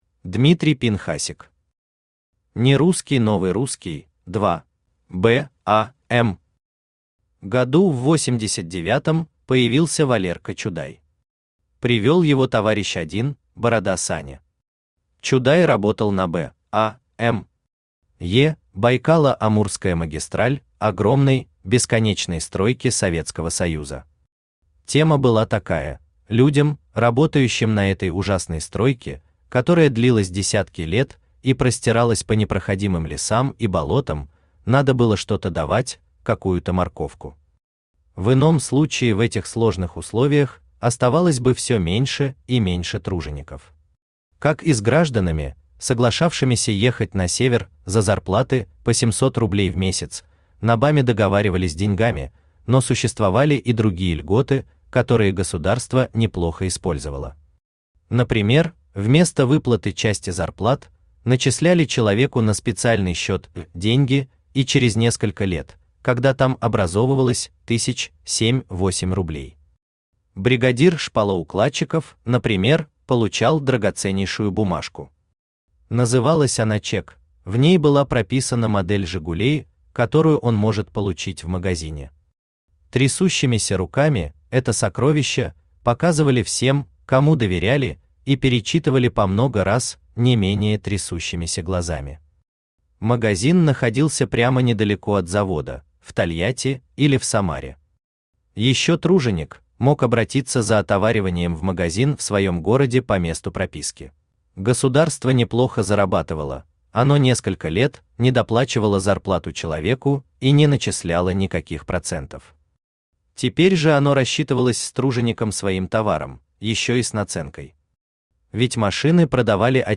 Аудиокнига Нерусский новый русский – 2 | Библиотека аудиокниг
Aудиокнига Нерусский новый русский – 2 Автор Дмитрий Евгеньевич Пинхасик Читает аудиокнигу Авточтец ЛитРес.